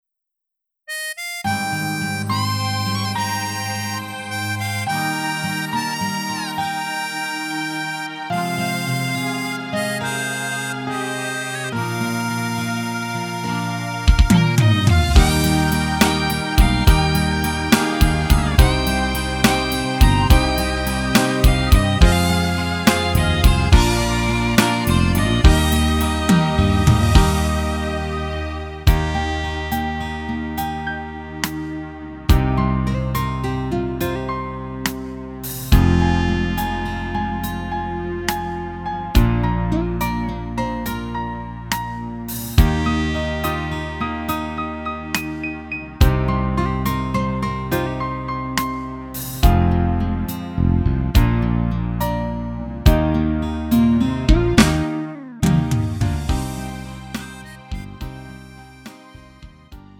음정 -1키 3:42
장르 구분 Lite MR